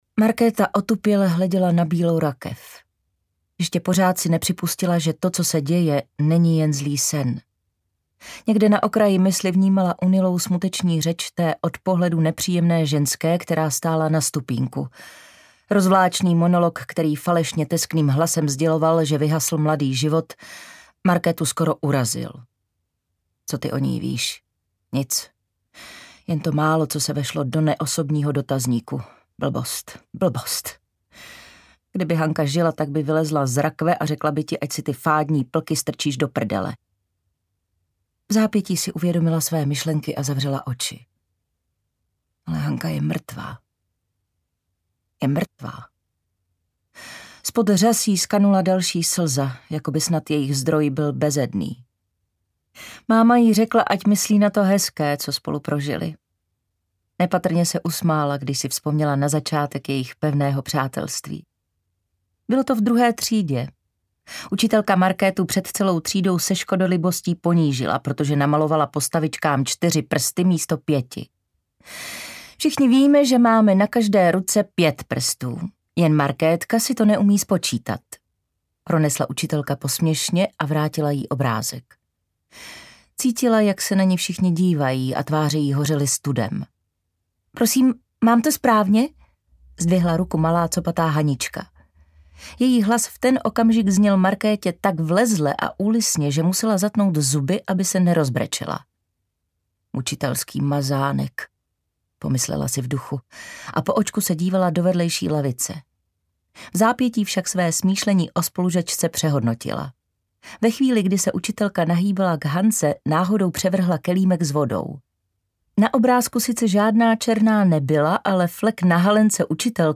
Bába audiokniha
Ukázka z knihy
• InterpretKlára Cibulková